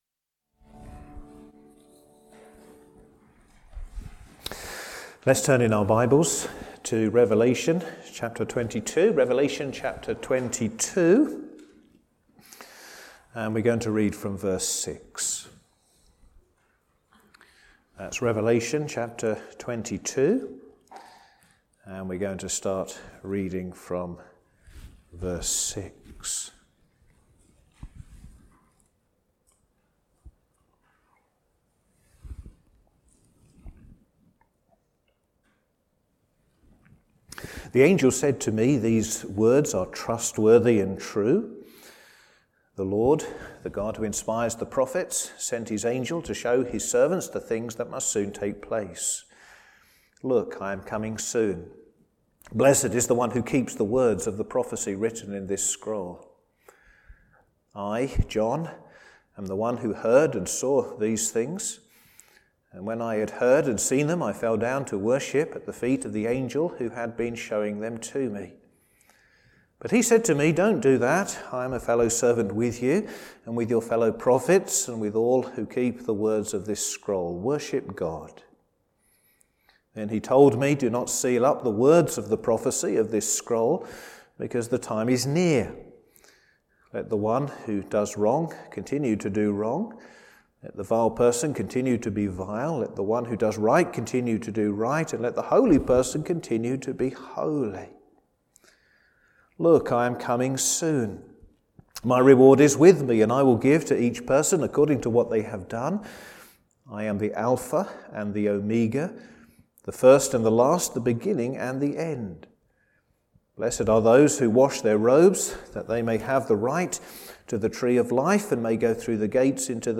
Sermon
Service Evening